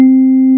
MS-20 VIBE01.wav